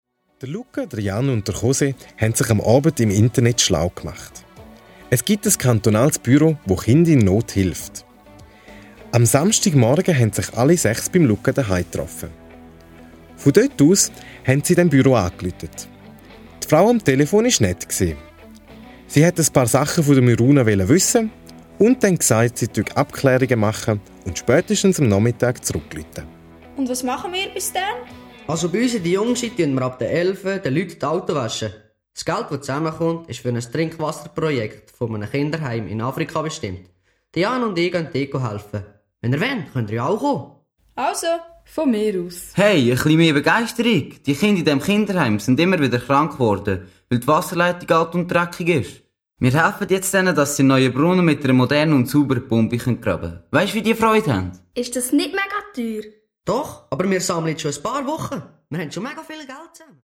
Hörspiel ab 8 Jahren